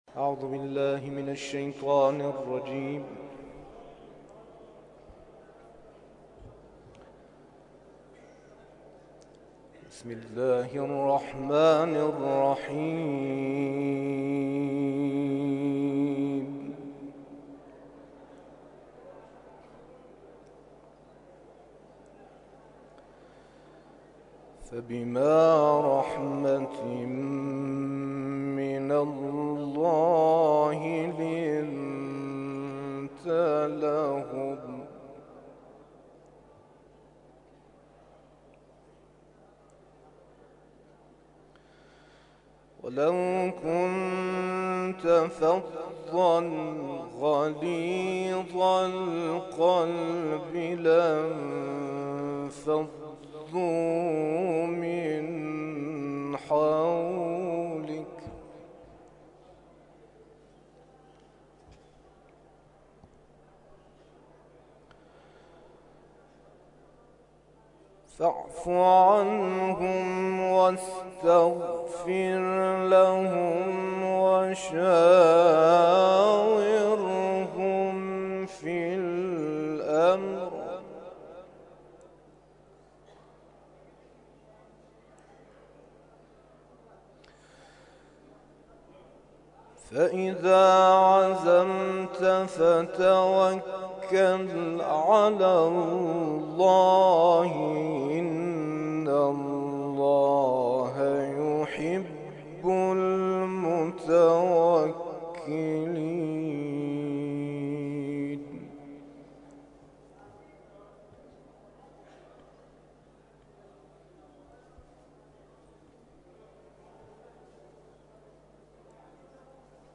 نماز جمعه
محافل و مراسم قرآنی
تلاوت قرآن کریم